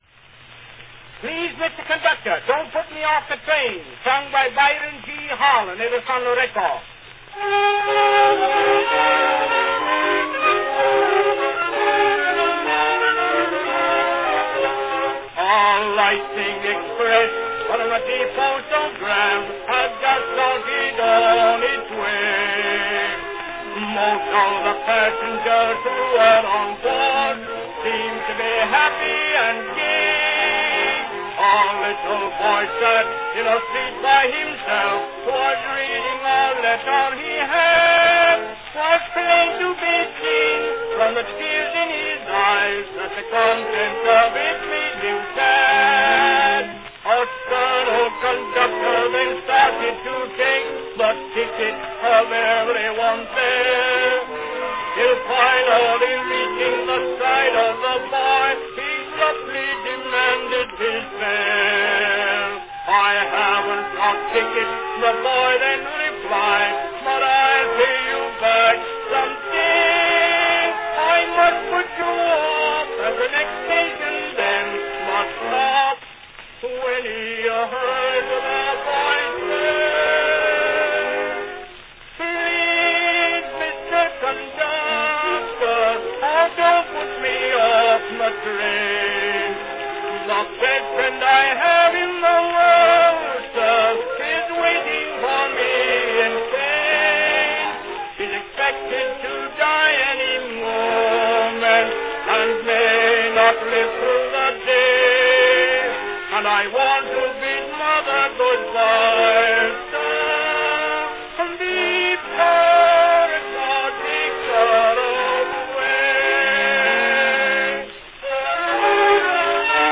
the classic sentimental song
Category Tenor solo